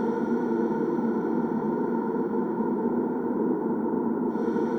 SS_CreepVoxLoopB-01.wav